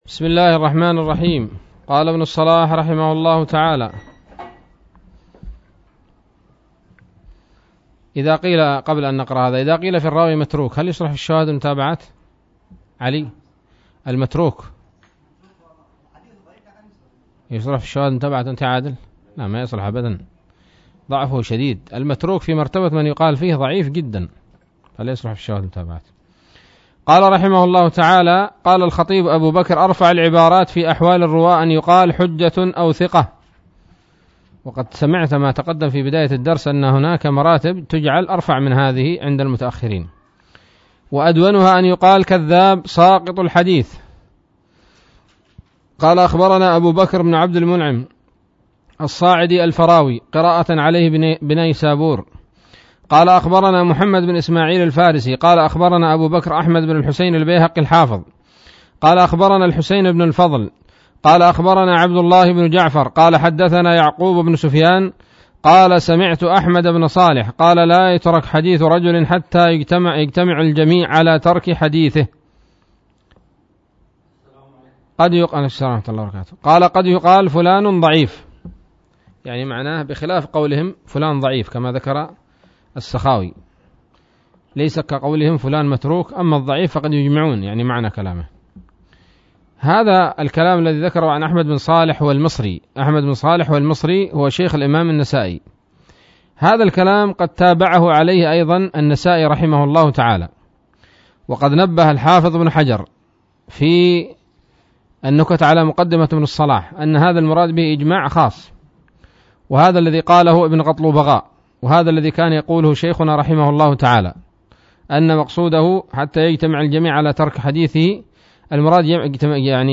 الدرس السابع والخمسون من مقدمة ابن الصلاح رحمه الله تعالى